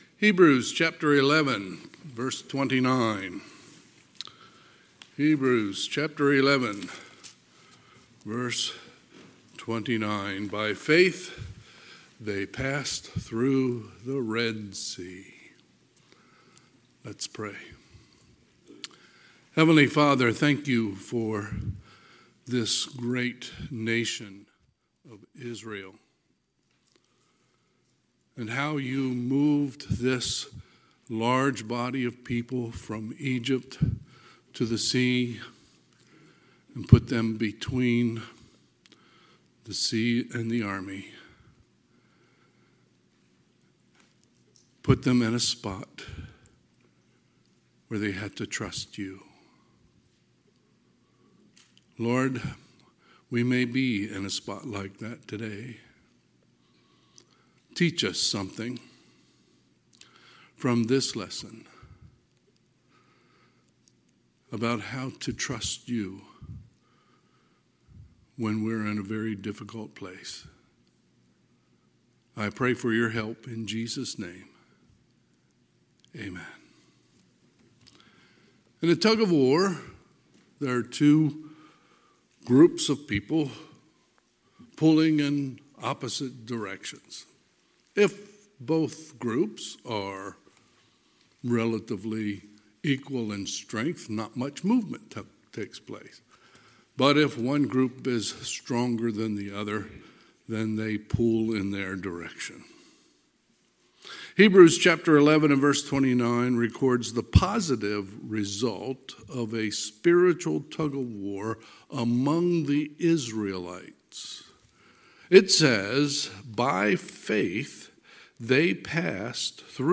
Sunday, November 3, 2024 – Sunday AM